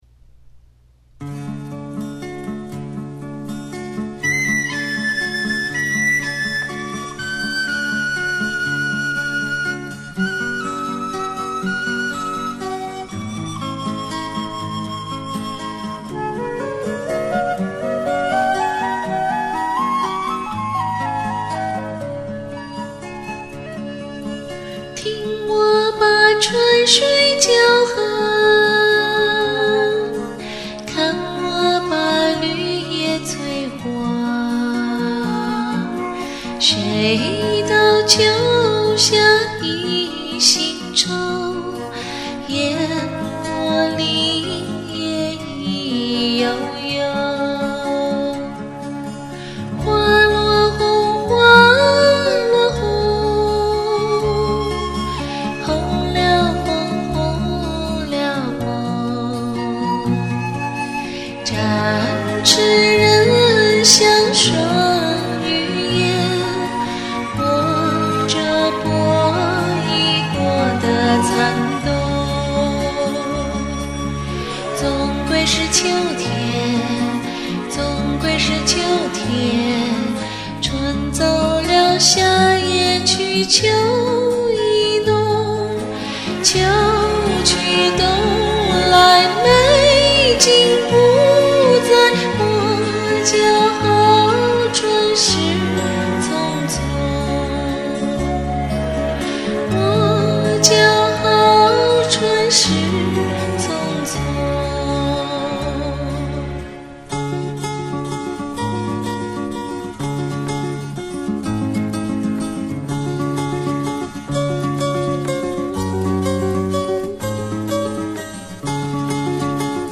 翻唱